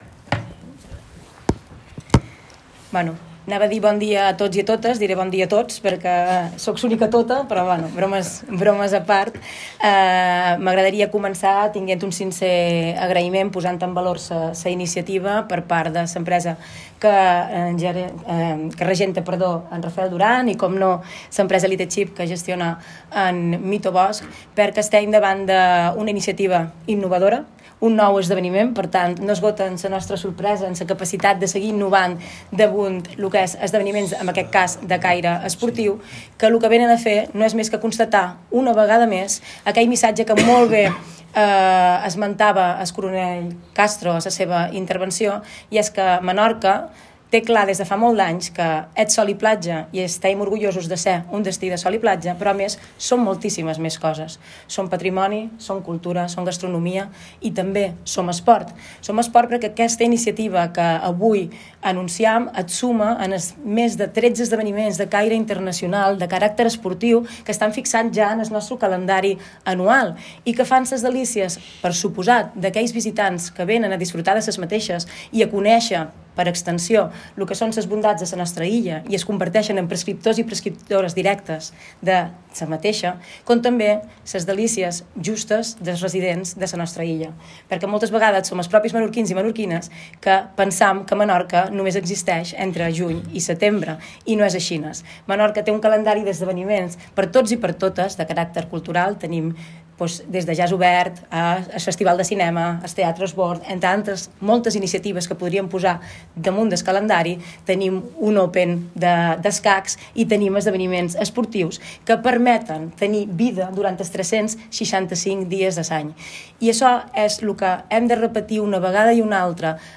La presidenta del Consell Insular de Menorca, Susana Mora, he remarcado la importancia del calendario de eventos muy amplio que tiene Menorca, tanto deportivos como culturales y que permiten dar vida a la isla los 365 días del año, poniendo en valor el concepto de sostenibilidad para poder posicionarnos y tener futuro.